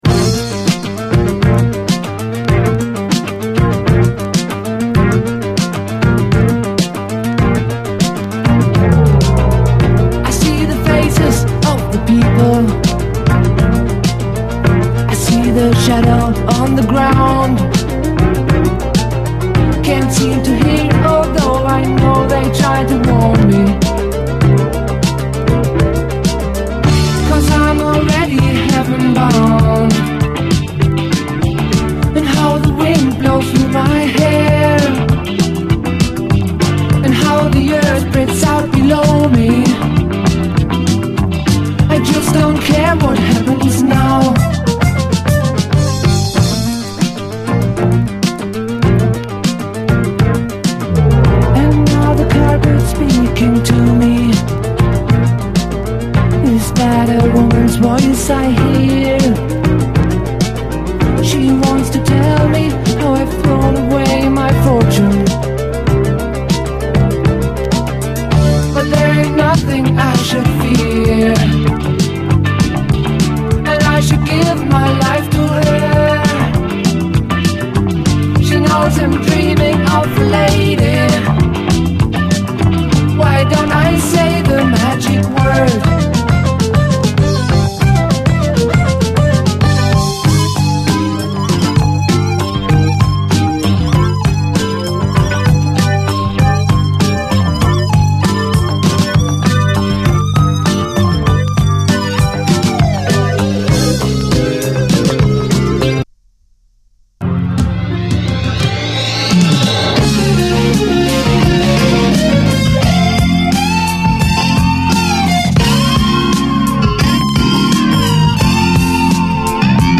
70's ROCK, DISCO, SSW / AOR, ROCK
シンセ・ロッキン・ディスコ